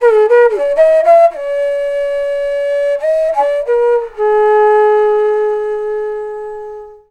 FLUTE-A11 -L.wav